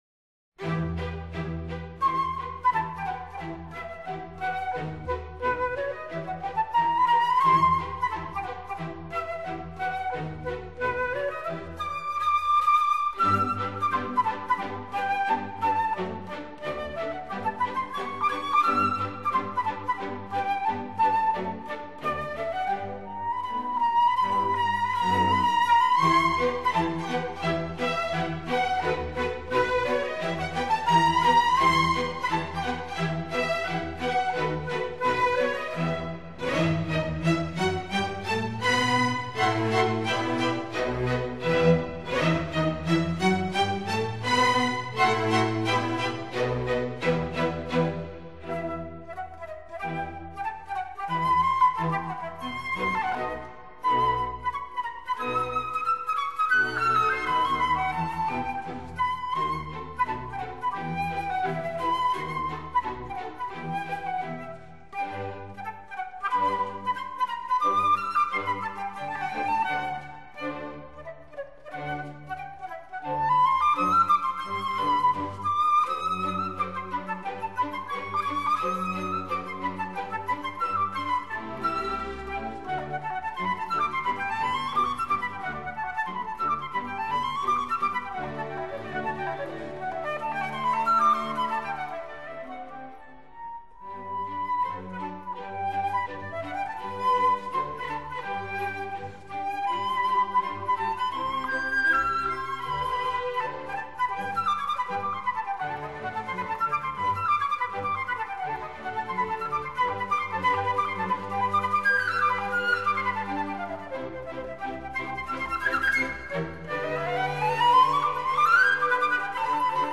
Flute Concerto E minor Allegro vivace, scherzando